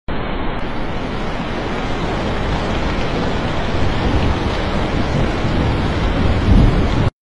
Embracing The Rain From The Sound Effects Free Download